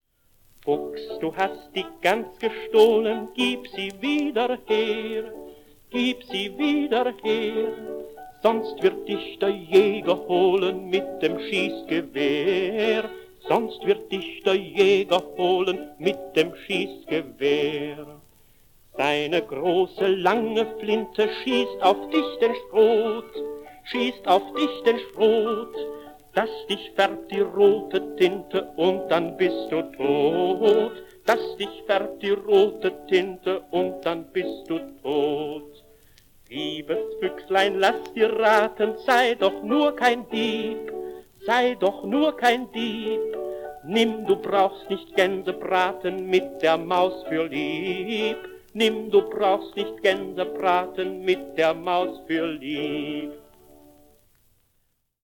deutschsprachiges Kinderlied